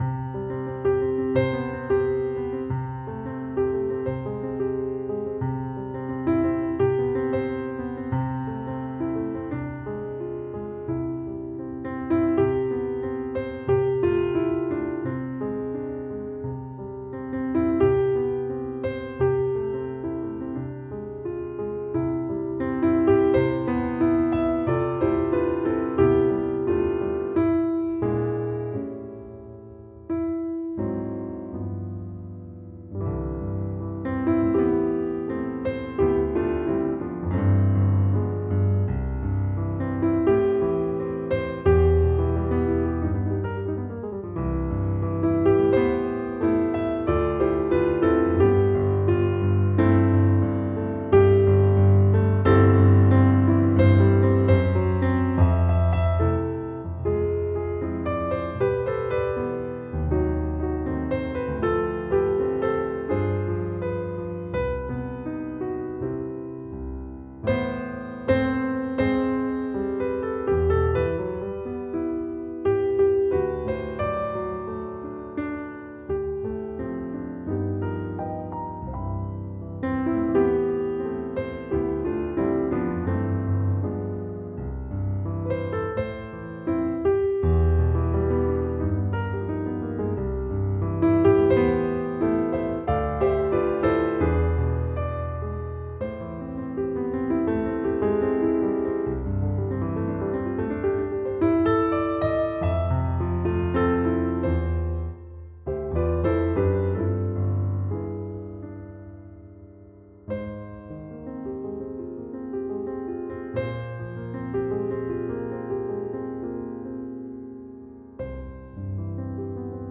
pour son ambiance relaxante